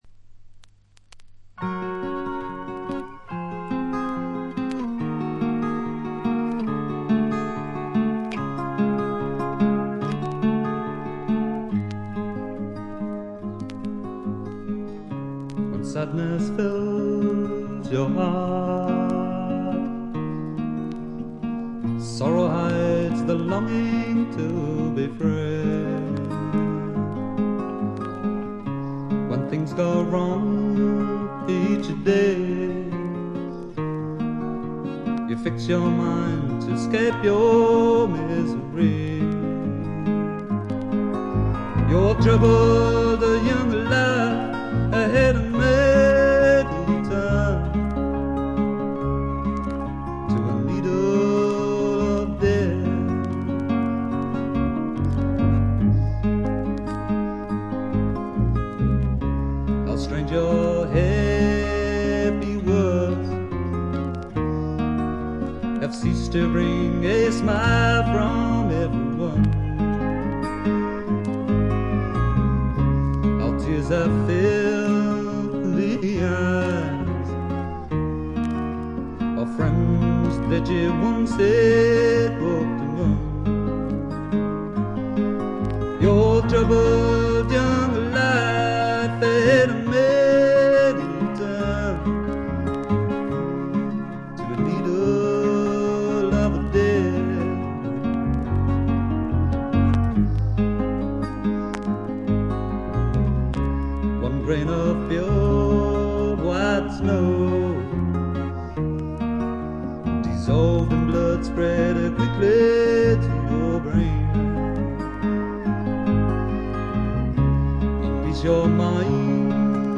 チリプチがそこそこ。散発的なプツ音も少し。
試聴曲は現品からの取り込み音源です。